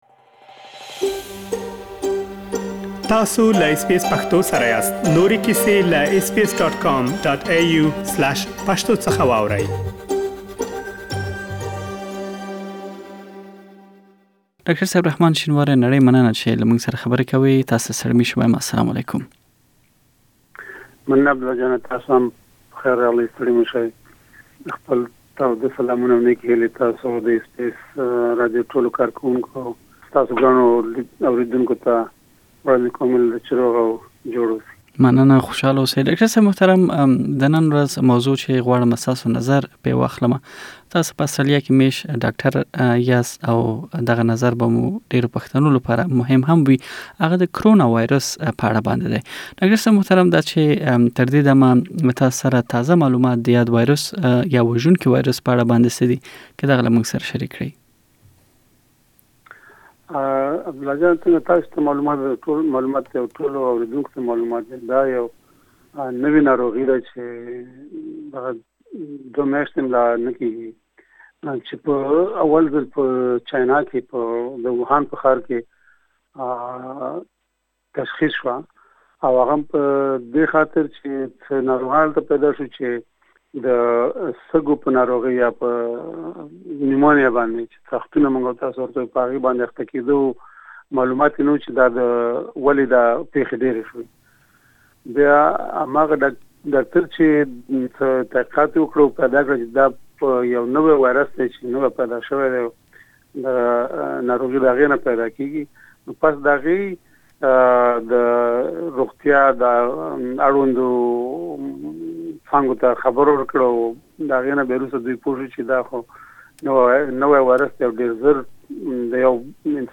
تاسې به پدې مرکه کې پدې پوه شئ چې د ياد وايروس نښې کومې دي، څنګه پيدا کيږي او څنګه يې مخنيوی کولی شئ؟